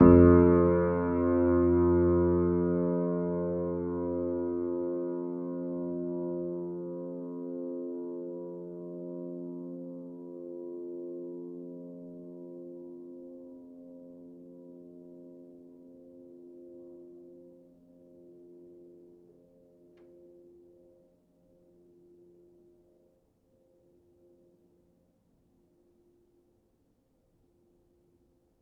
Upright Piano